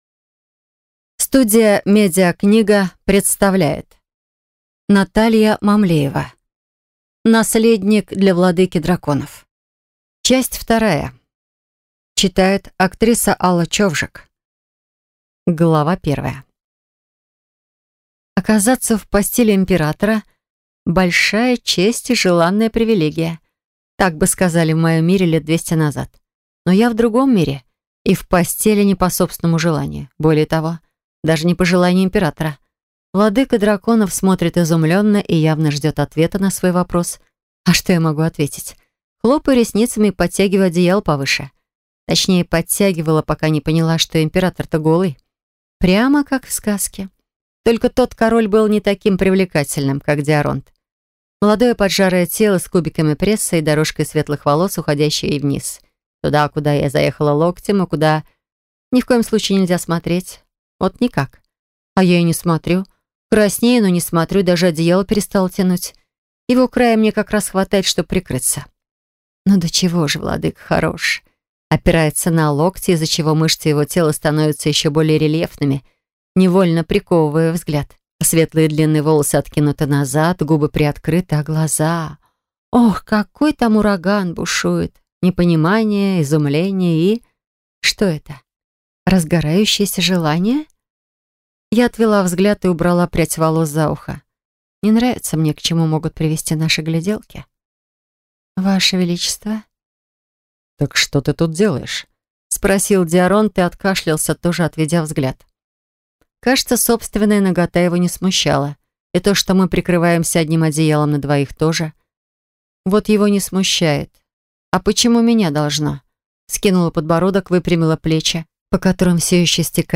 Аудиокнига Наследник для Владыки Драконов 2 | Библиотека аудиокниг
Прослушать и бесплатно скачать фрагмент аудиокниги